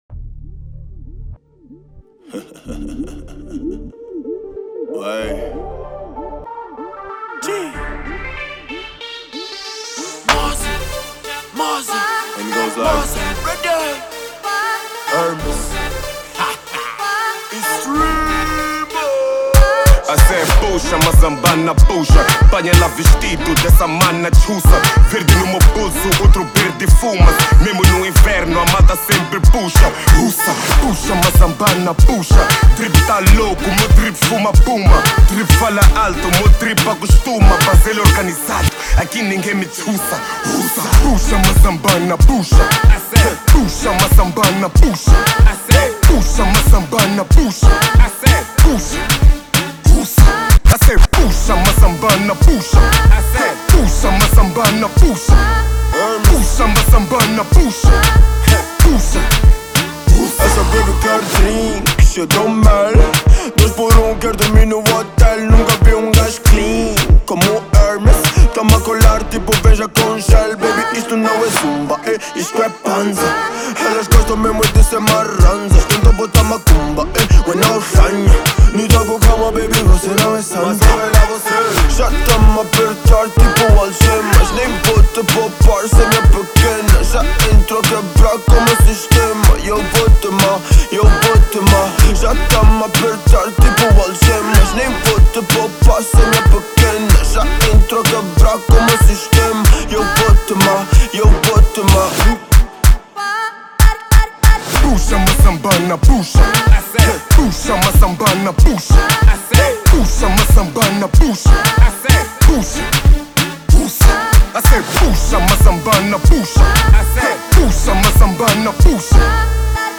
Genero: Pop